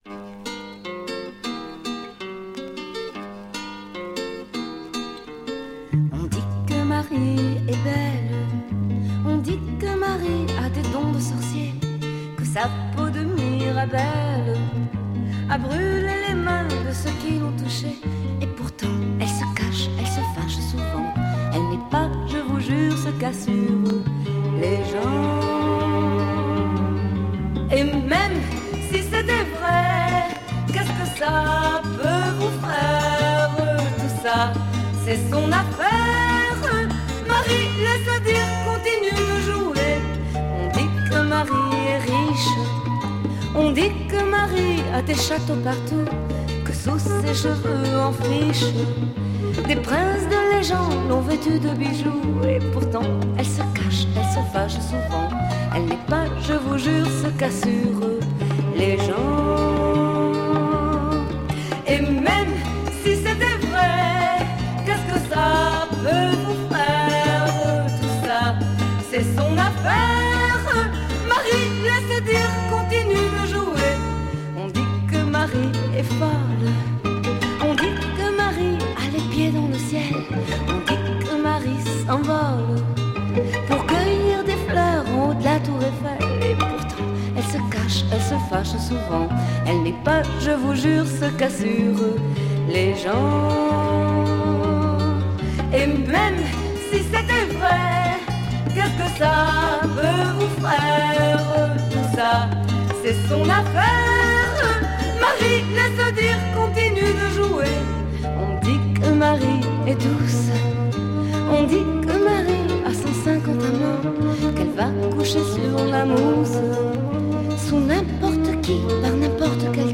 French Female Pop-sike folk